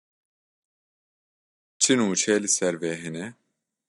/nuːˈt͡ʃɛ/